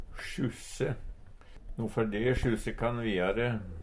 DIALEKTORD PÅ NORMERT NORSK sjusse skysse Infinitiv Presens Preteritum Perfektum sjusse sjussa sjussa sjussa Eksempel på bruk No fær me sjusse kann viare.